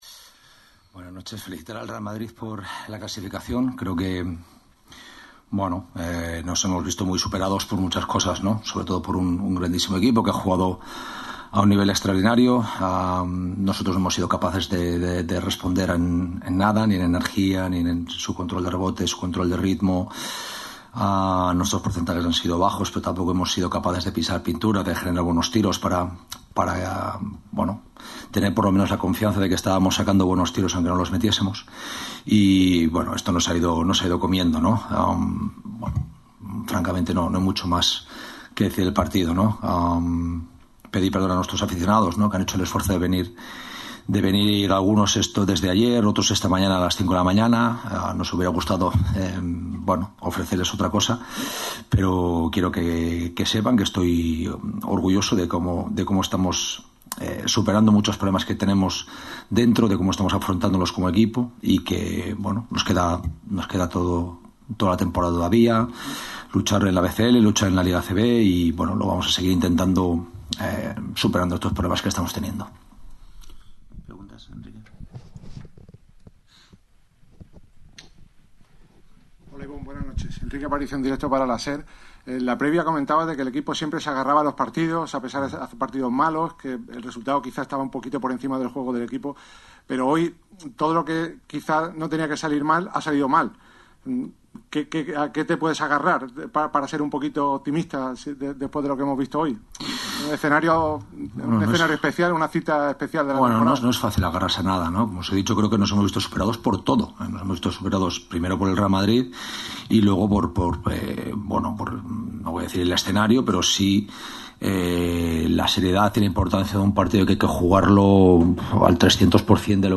Posterior a dicha declaración, atendió a los medios en una rueda de prensa muy seria por parte del vitoriano.
Declaraciones de Ibon Navarro tras la debacle en Copa del Rey